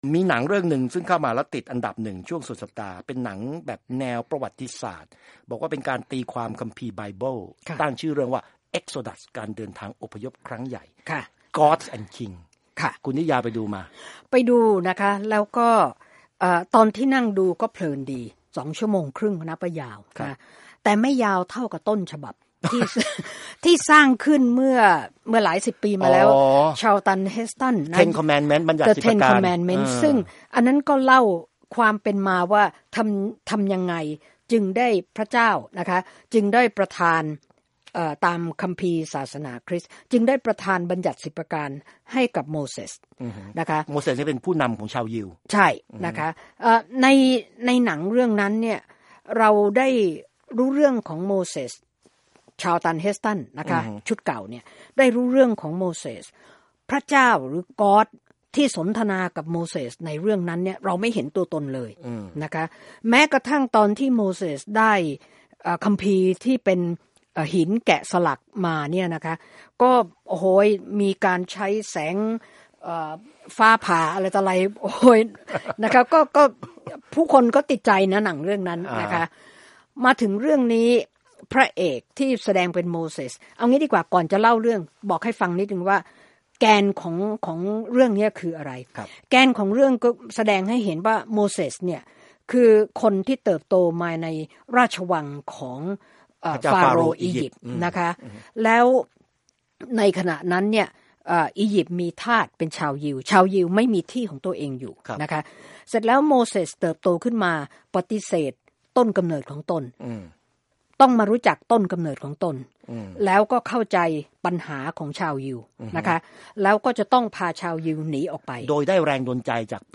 Movie Review: Exodus Gods and Kings